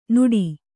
♪ nuḍi